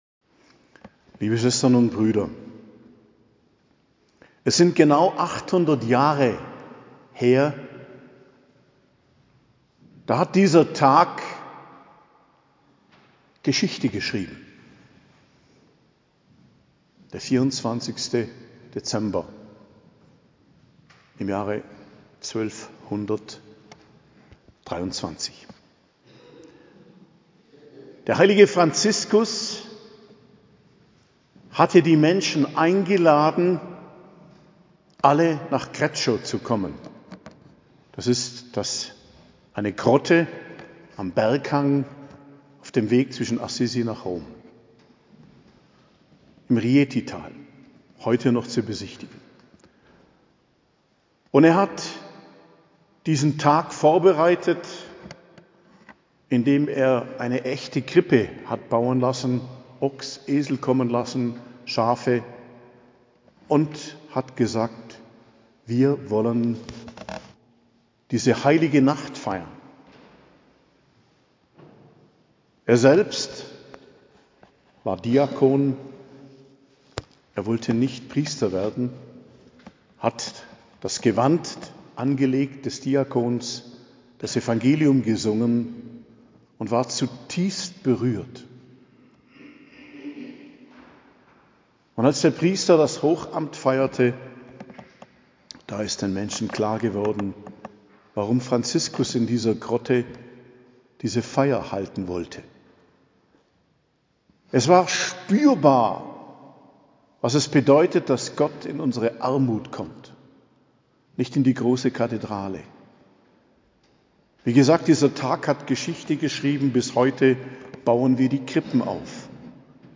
Predigt zum 4. Adventssonntag, 24.12.2023 ~ Geistliches Zentrum Kloster Heiligkreuztal Podcast